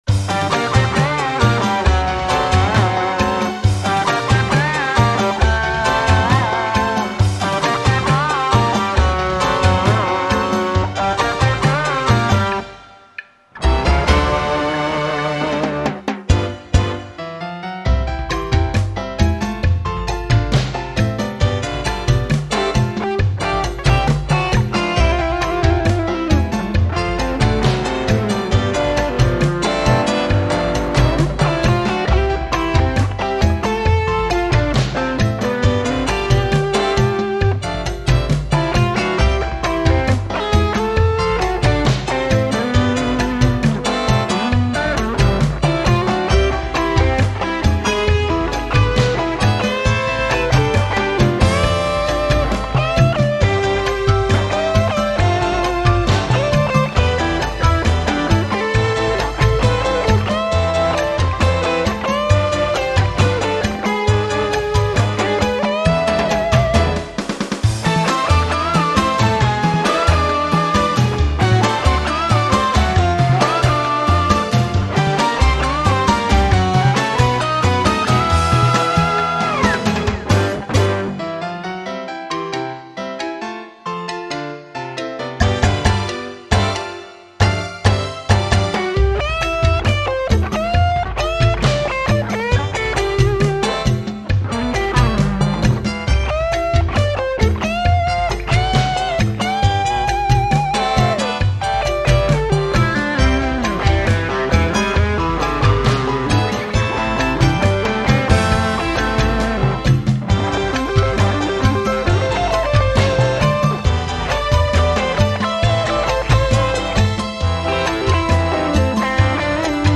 oompah and belly dance